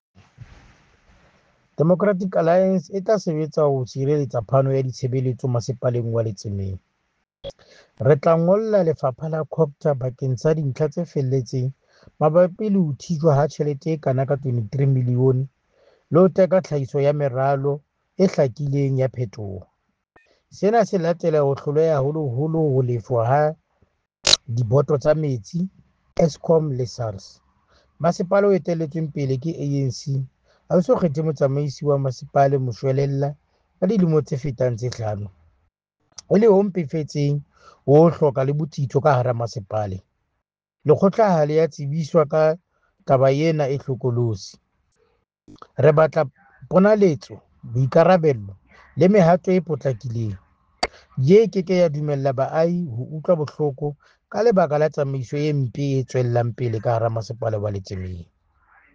Sesotho soundbites by Cllr Thabo Nthapo and